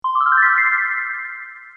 SFX高兴清脆悦耳的音效下载
SFX音效